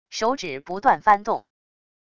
手指不断翻动wav音频